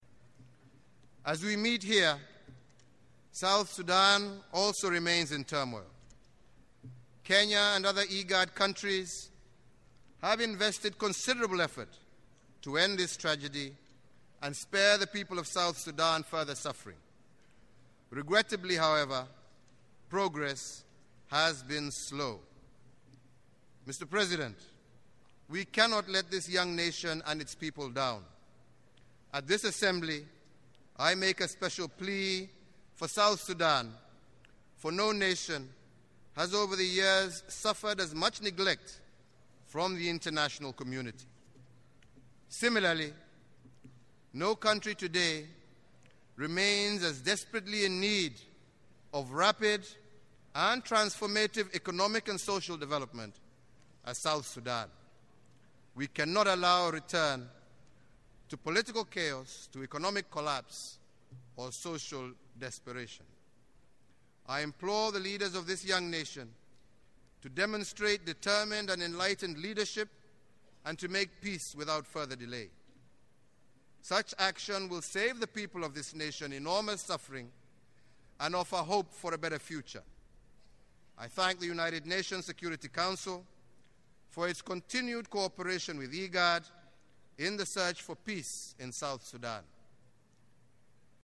Kenyan President Uhuru Kenyatta addresses the 69th session of the U.N. General Assembly in New York on Weds., Sept. 24, 2014.
Kenyan President Uhuru Kenyatta address UNGA on South Sudan